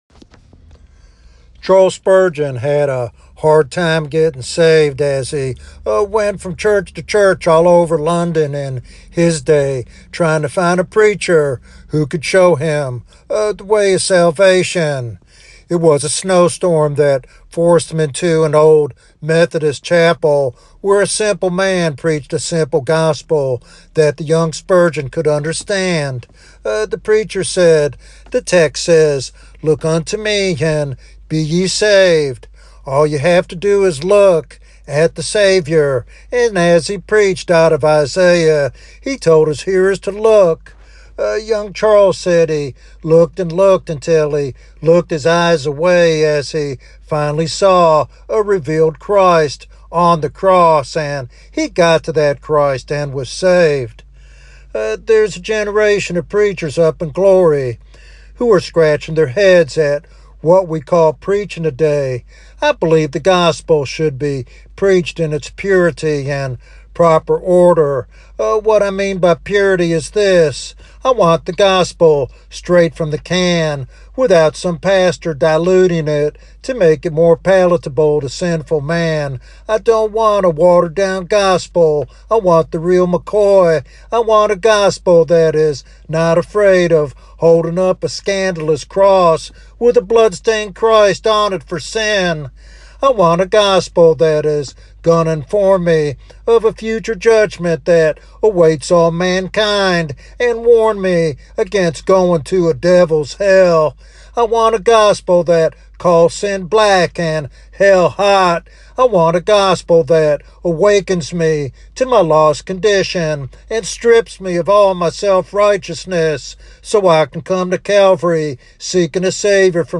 This sermon serves as a powerful reminder of the gospel's transformative power when preached faithfully.